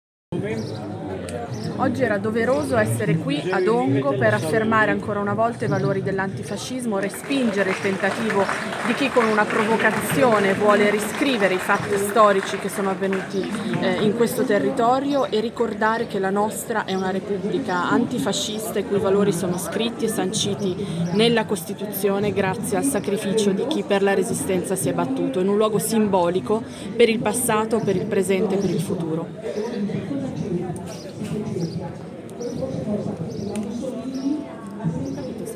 Dichiarazioni alla fine della manifestazione
Chiara Braga, capogruppo Pd Camera.